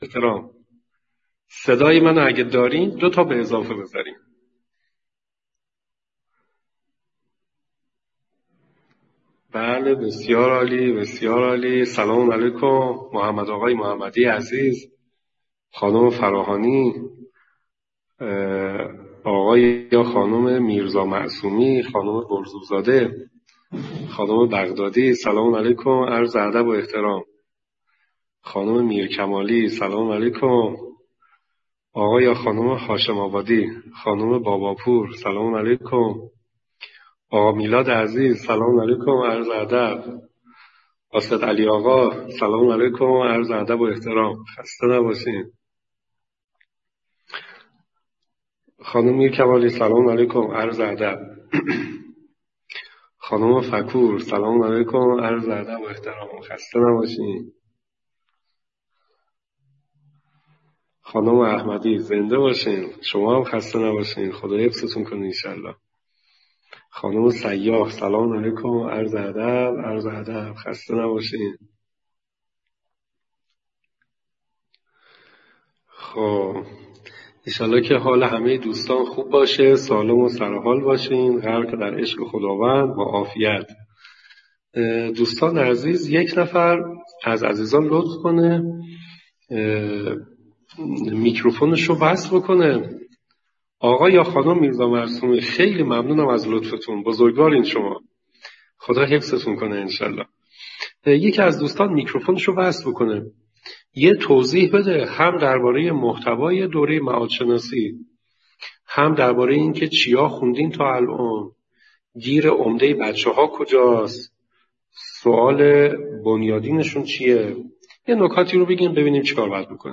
فرجام شناسی - تا-بی‌نهایت،-پرسش-و-پاسخ